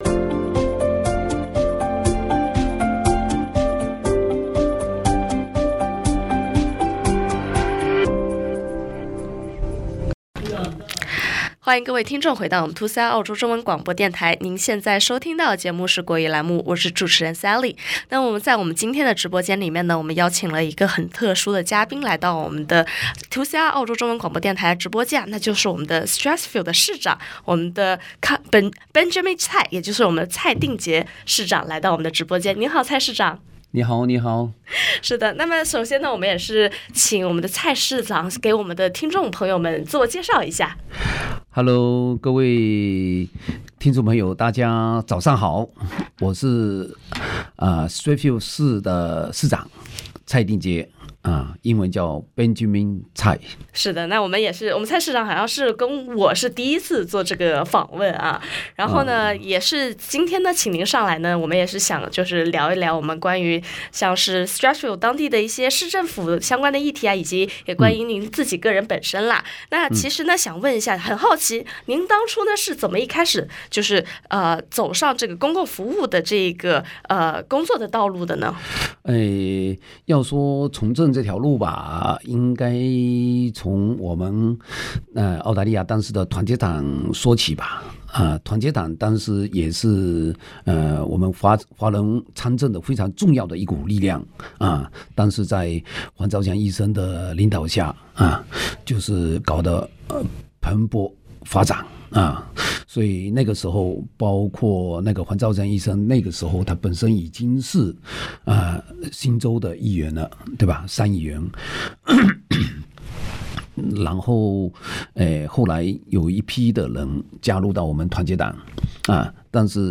《專訪 Strathfield 市長蔡定傑》——紮根社區二十年 華人從政需要更多年輕力量
2CR 澳洲中文廣播電台日前專訪了新任 Strathfield 市長蔡定傑（Cr Benjamin Cai）。
訪問Strathfield市長蔡定傑Benjamin-Cai.mp3